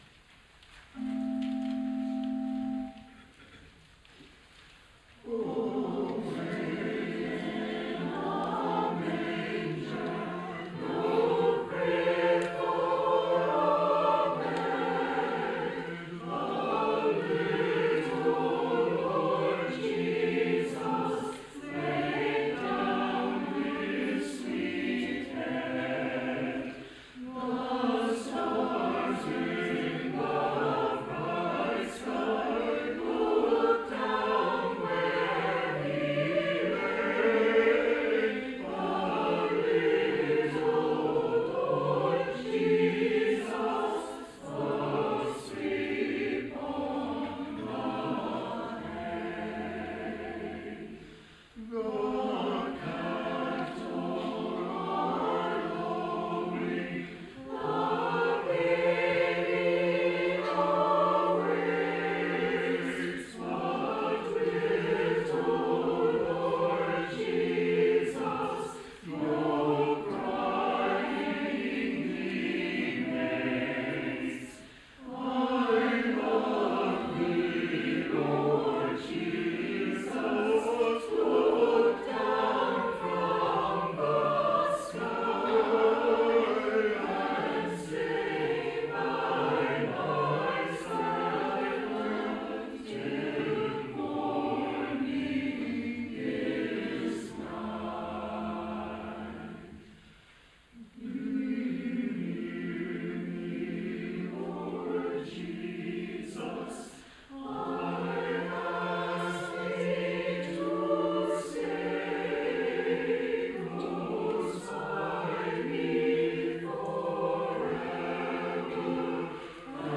Sung by the FMC Chancel Choir
Carol+Sing+25+-+Choir.wav